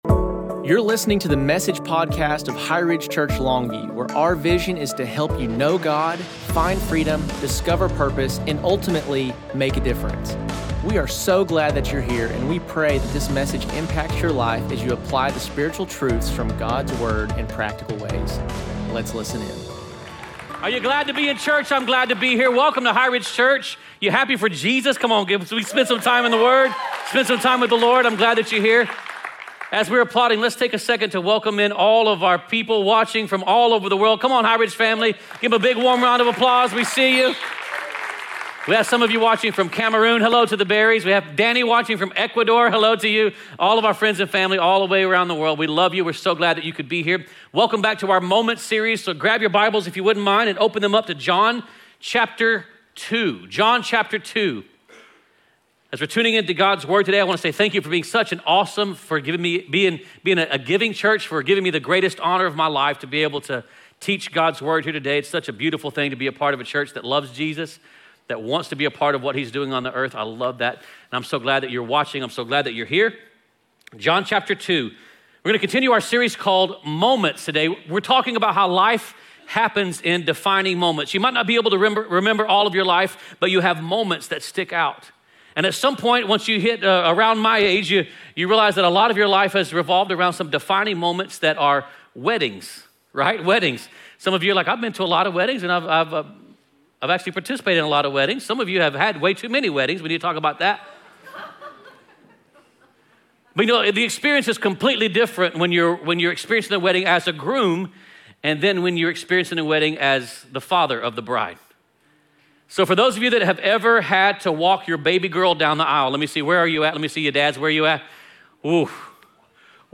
2025 Message